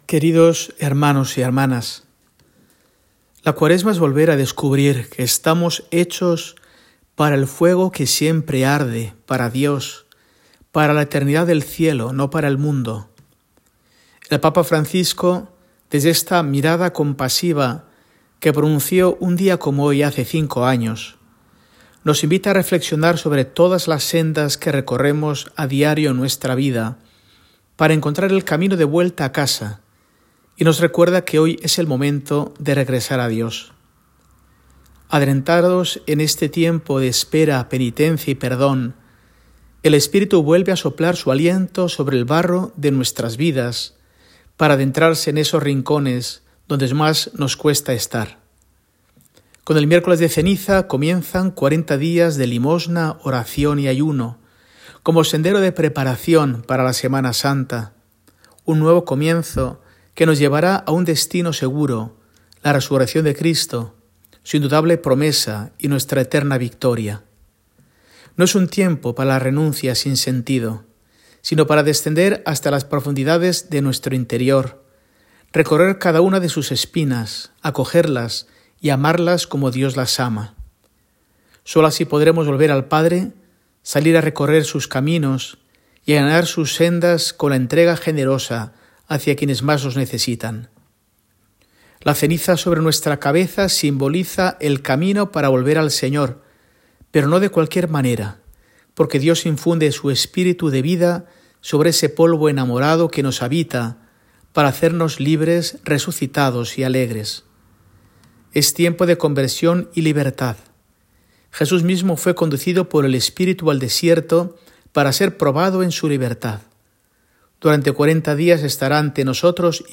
Arzobispo de Burgos